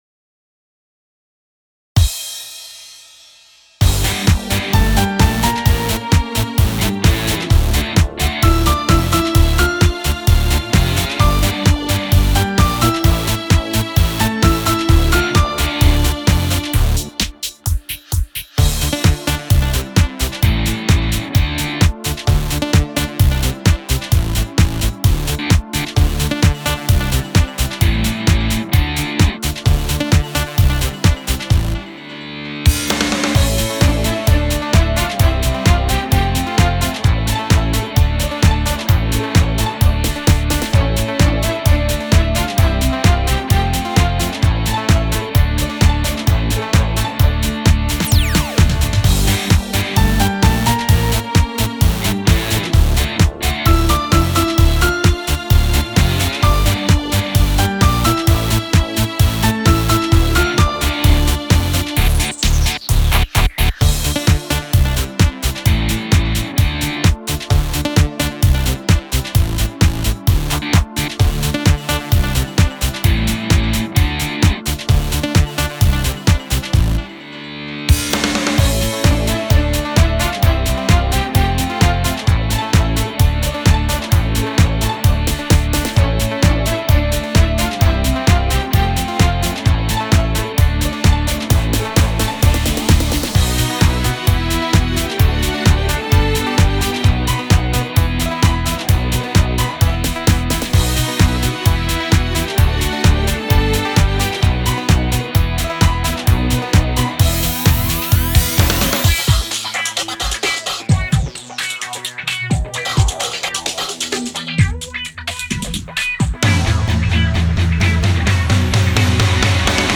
караоке